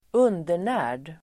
Ladda ner uttalet
Uttal: [²'un:der_nä:r_d]